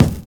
VINYL 5 BD.wav